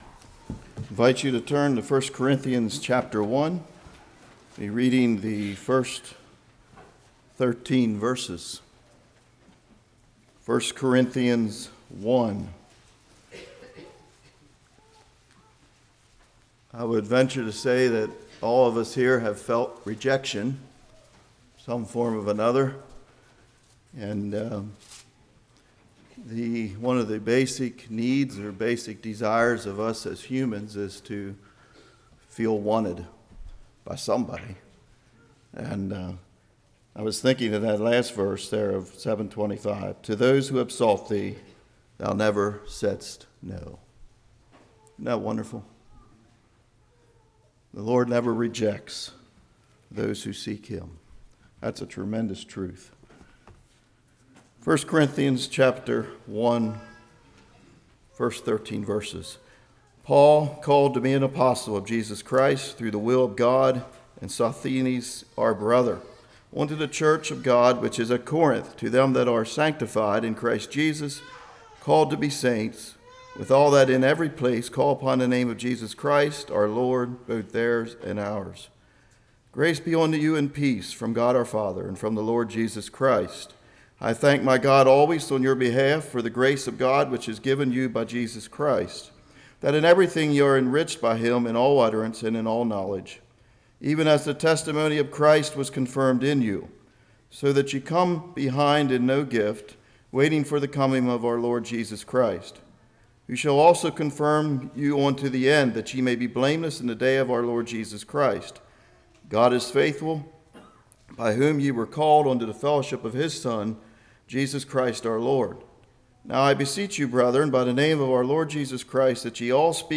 Service Type: Morning